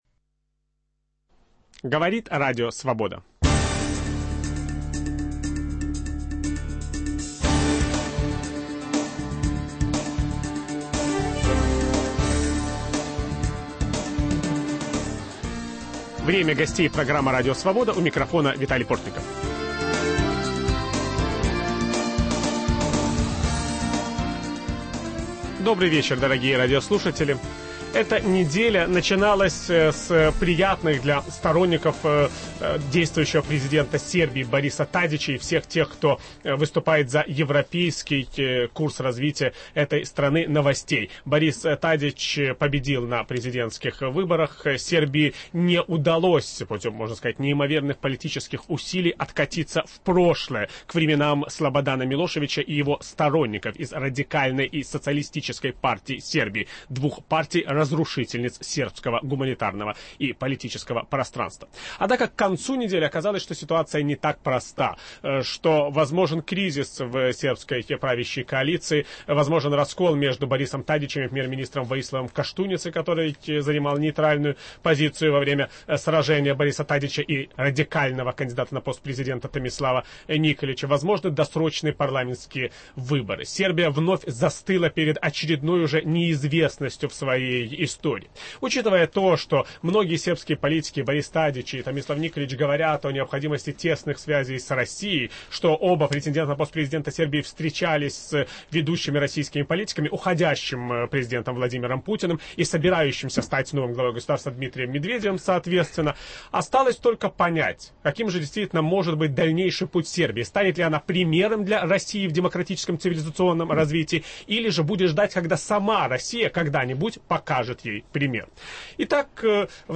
И как будут строиться отношения Сербии и России? Об этом ведущий программы Виталий Портников беседует с известными балканистами